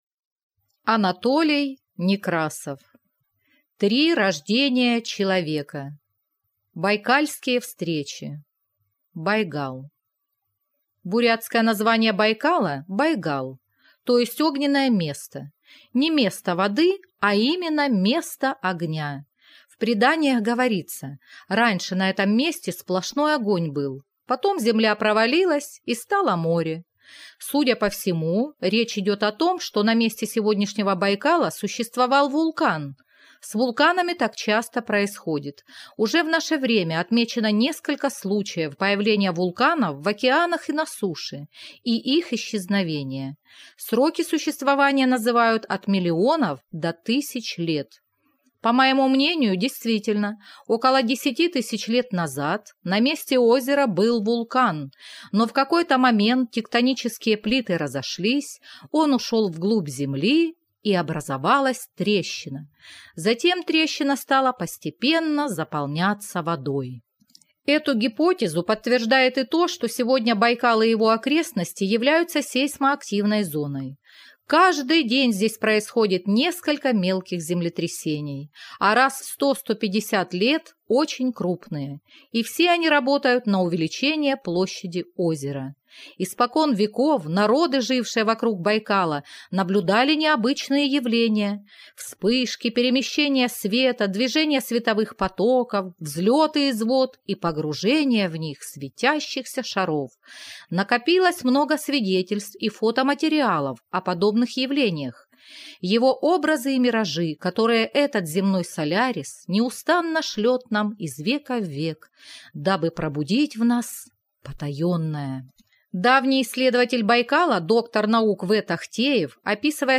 Аудиокнига Три рождения человека. Байкальские встречи | Библиотека аудиокниг